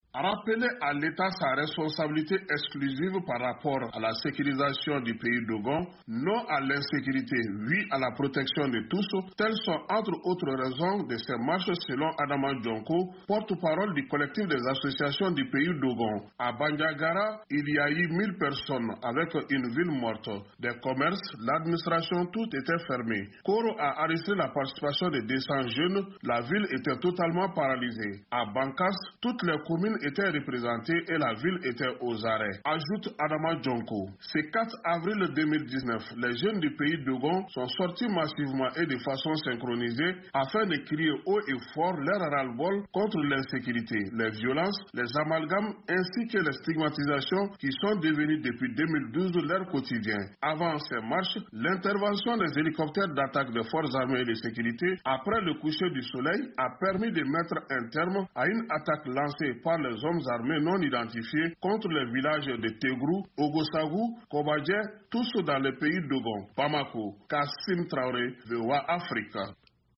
Cette manifestation fait suite au massacre d'Ogossagou et aux récentes attaques dans leurs différentes localités. Le compte rendu